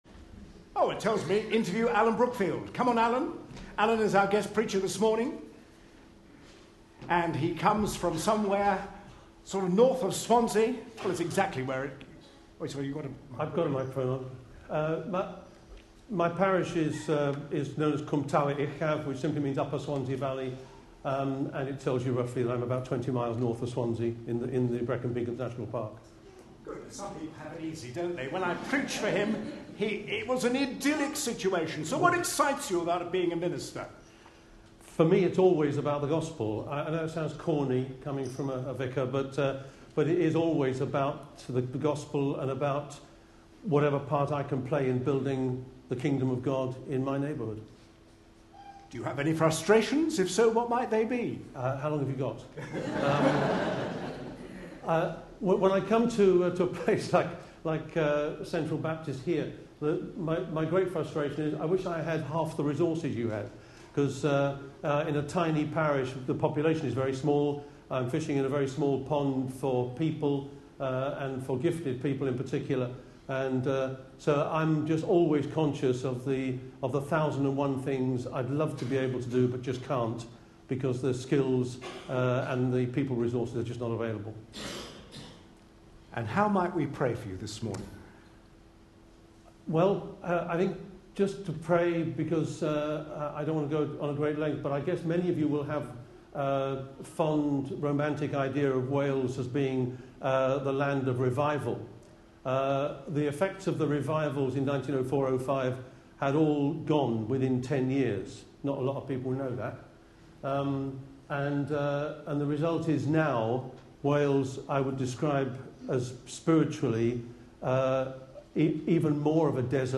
A sermon preached on 3rd February, 2013.